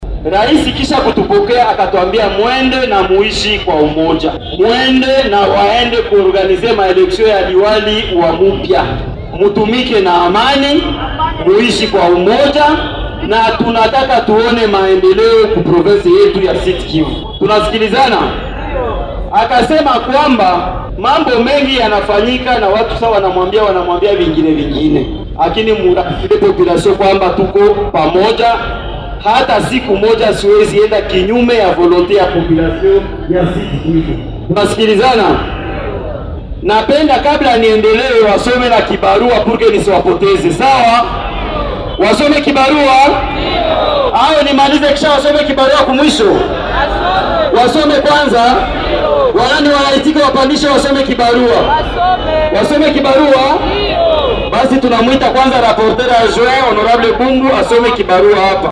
Aussitôt accueillis par la population qui est venue en masse au port Ihusi, les députés provinciaux se sont rendus à la place de l’indépendance, où ils ont tenu un meeting
Devant le public, les élus provinciaux, par le biais du rapporteur de l’assemblée provinciale du Sud-Kivu, Amani Kamanda Jacques, ont briefé leur base électorale concernant leur séjour à Kinshasa